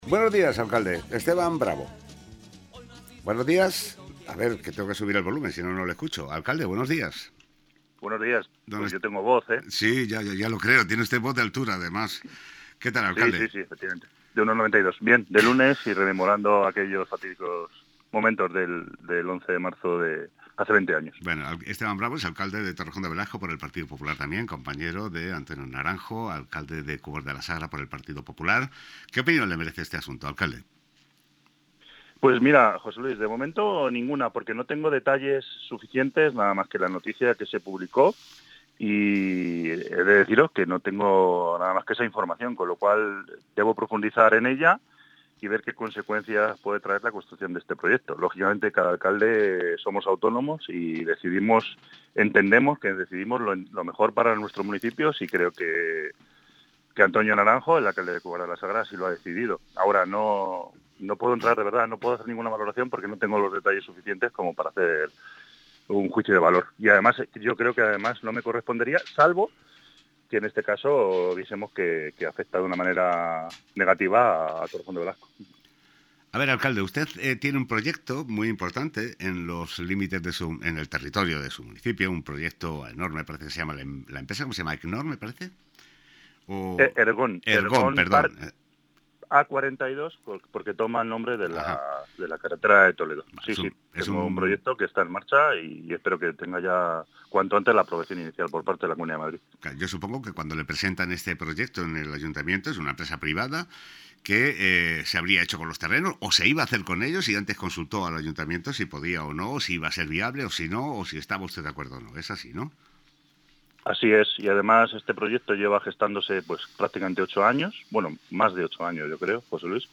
Entrevista al Alcalde de Torrejón de Velasco sobre la planta de residuos de Cubas – GLOBOFM
Esteban Bravo, Alcalde del PP en Torrejón de Velasco, ha pasado esta mañana de lunes por los micrófonos de GloboFM para ofrecer una pequeña intervención sobre los hechos referentes a la planta de residuos que se prevé crear en el municipio colindante de Cubas de la Sagra.
Aquí la entrevista completa: